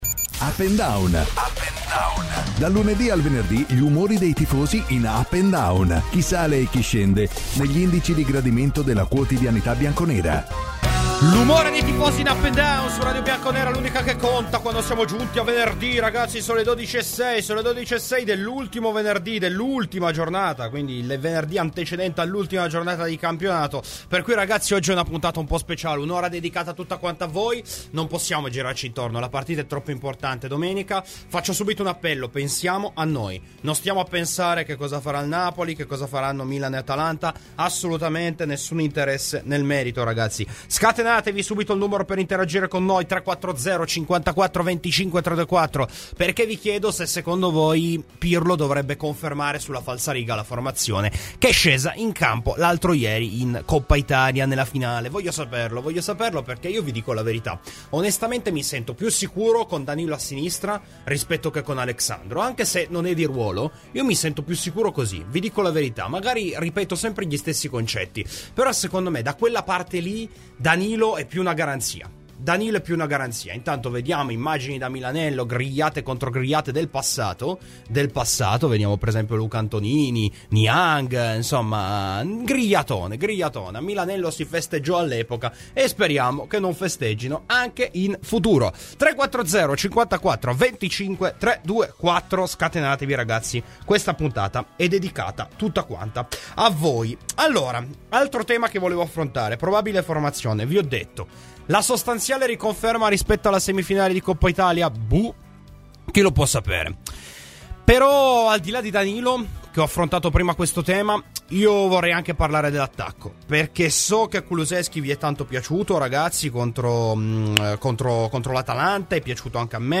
Intervistato da Radio Bianconera
Clicca sul podcast in calce per ascoltare la trasmissione integrale.